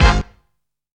FABULOUS HIT.wav